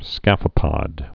(skăfə-pŏd)